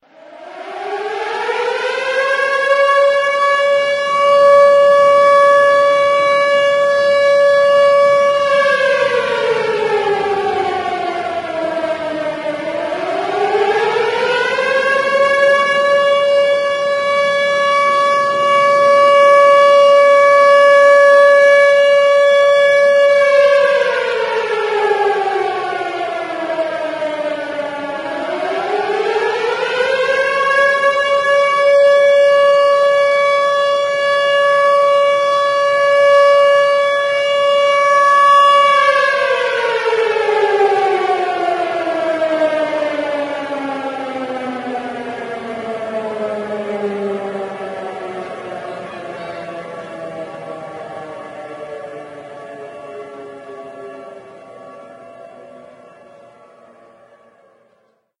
siren1.ogg